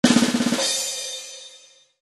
Звуки тарелки
На этой странице собраны звуки тарелок – яркие, резонансные и динамичные.
Барабанная дробь и тарелка в финале